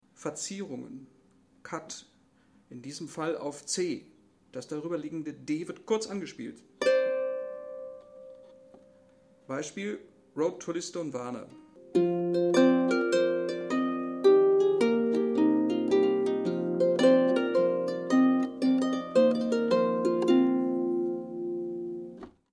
Vor dem eigentlich notierten Ton wird kurz der darüberliegende Ton, die Sekunde,  angespielt.